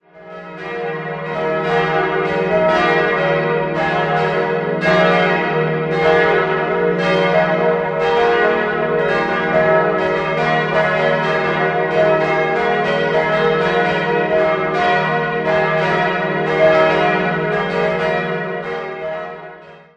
Sie wurde im Jahr 1949 eingeweiht. 4-stimmiges ausgefülltes Es-Dur-Geläute: es'-f'-g'-b' Die drei kleineren Glocken wurden 1949 von Karl Czudnochowsky in Erding gegossen, die große stammt von Friedrich Wilhelm Schilling (Heidelberg) aus dem Jahr 1962.